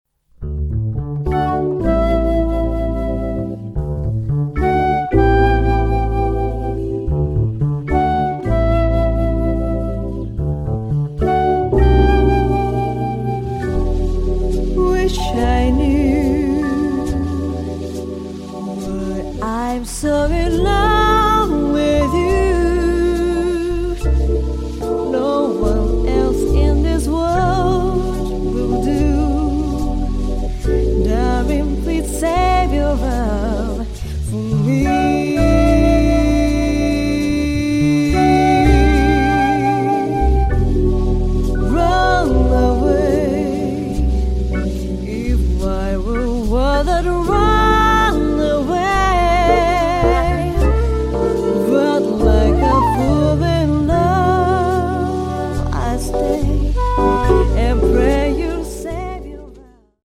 ジャズとソウルと日本と気品が見事に融合したバラードではないだろうか。